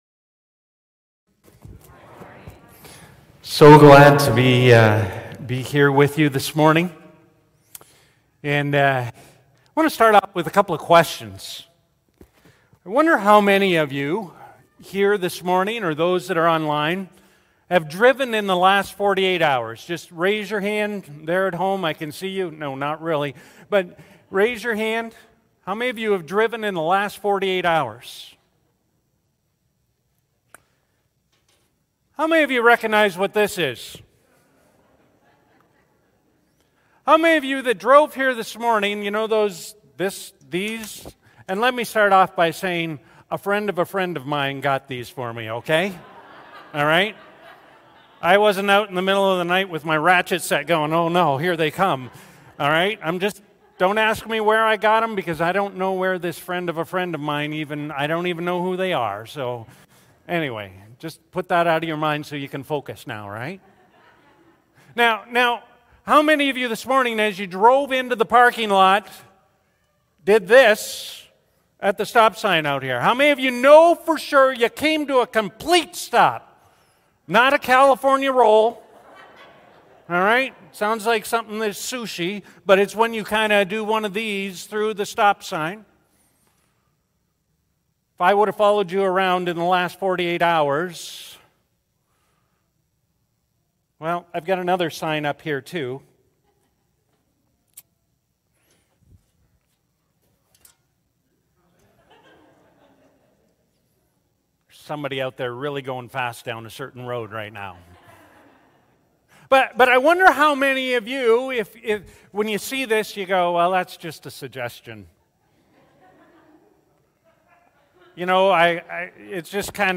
Sunday-Service-Feb-28-A-Superior-Covenant.mp3